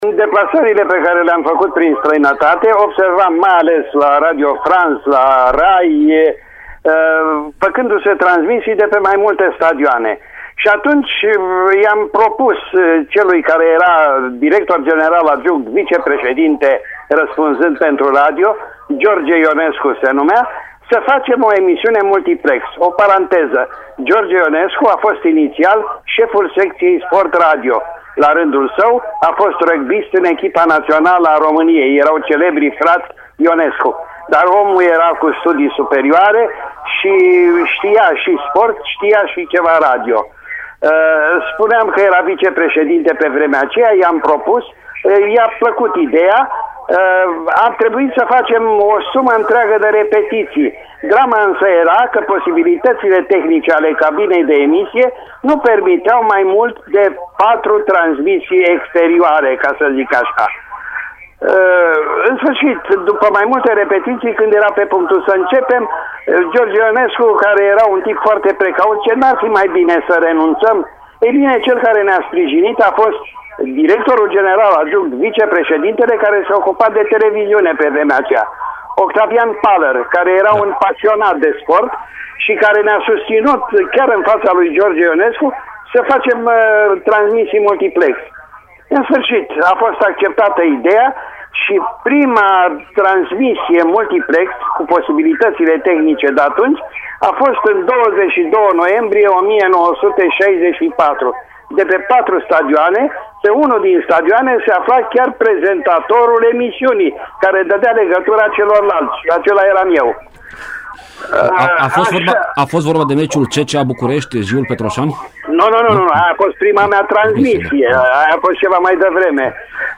În aceeași emisiune, difuzată în vara lui 2015 la Radio Timișoara, Ion Ghițulescu a rememorat primii pași ai emisiunii „Fotbal Minut cu Minut”: